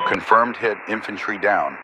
Added "infantry killed" radio messages
pilotKillInfantry4.ogg